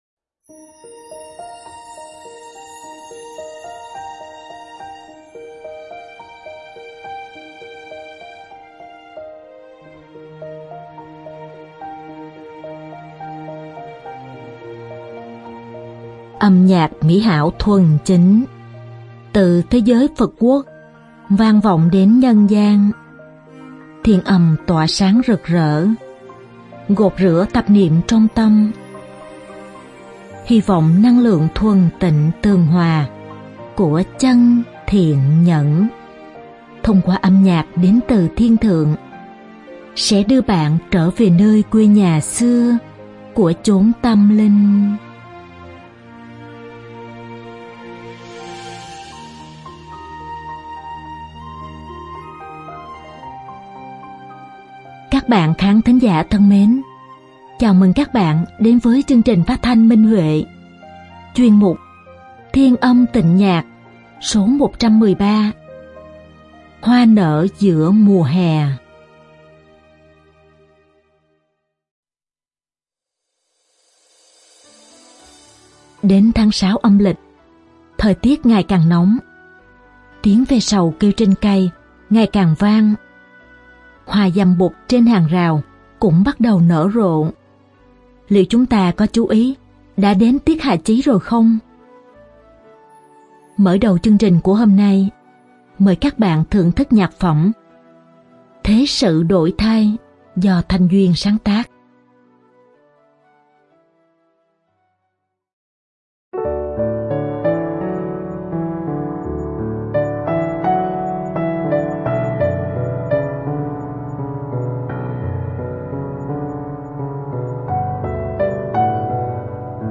Đơn ca nam
Đơn ca nữ